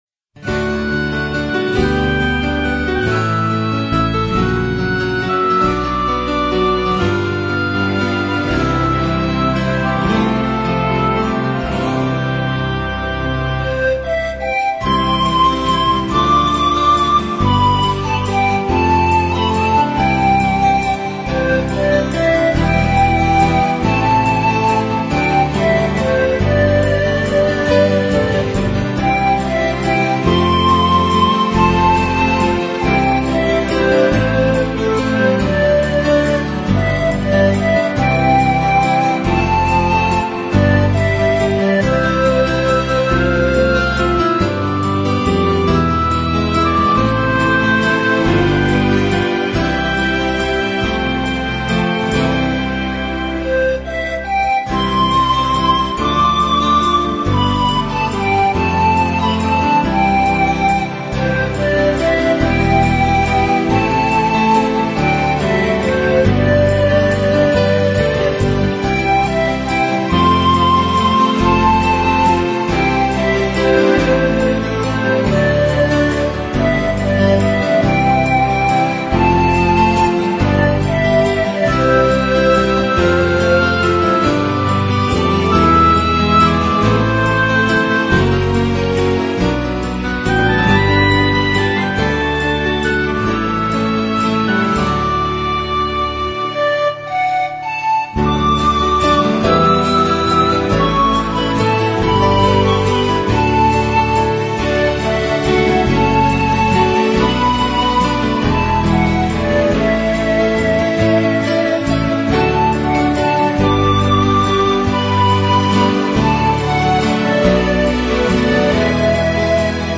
轻音乐专辑